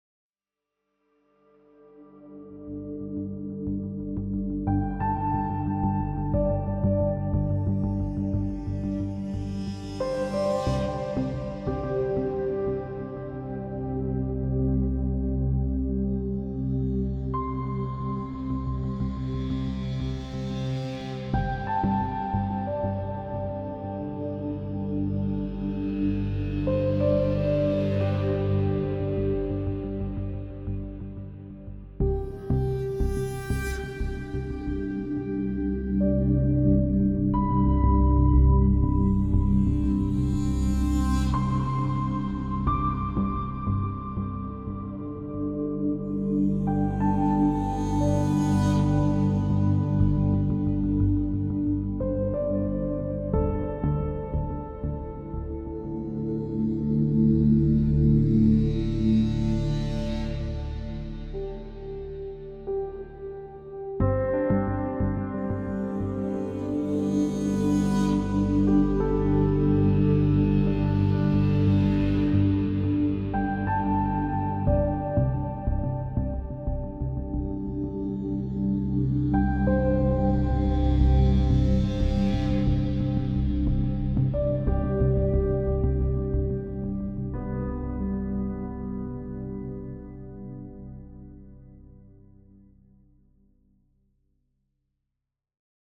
Added Ambient music pack. 2024-04-14 17:36:33 -04:00 26 MiB Raw Permalink History
Ambient Wonderful Main.wav